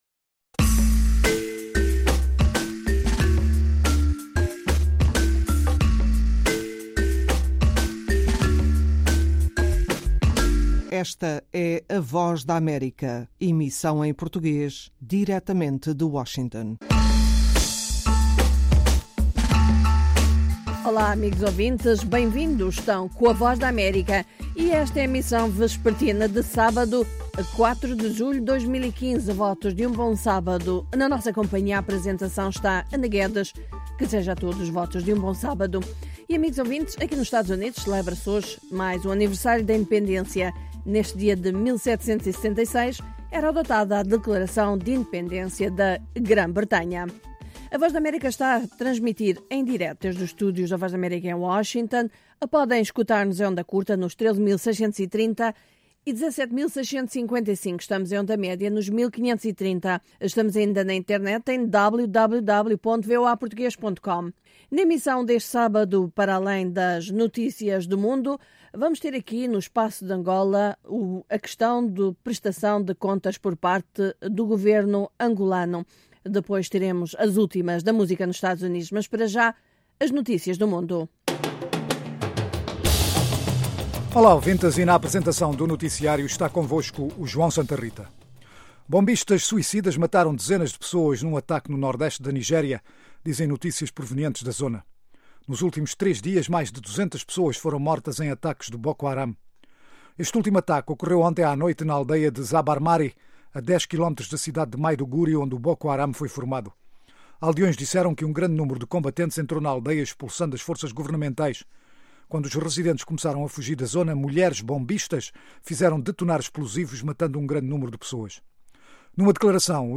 Meia-hora duas vezes por Aos sábados, ouça uma mesa redonda sobre um tema dominante da política angolana, música americana e as notícias do dia.